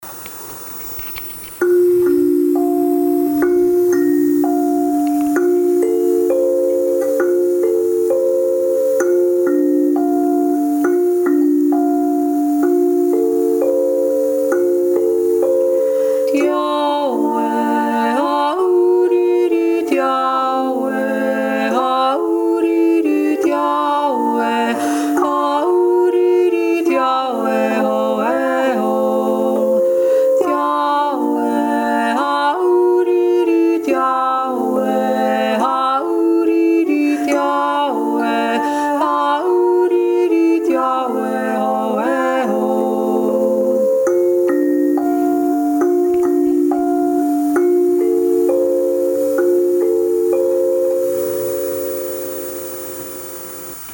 Die Jodler
2. Stimme